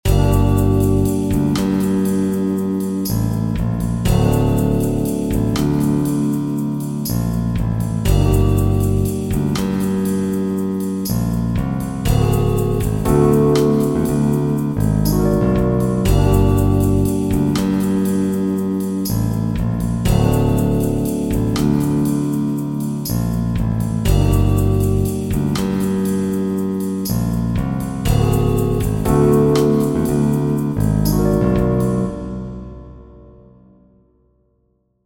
Short 120bpm loop in 15edo
15edo_demo.mp3